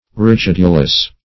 Search Result for " rigidulous" : The Collaborative International Dictionary of English v.0.48: Rigidulous \Ri*gid"u*lous\, a. [Dim. from rigid.]
rigidulous.mp3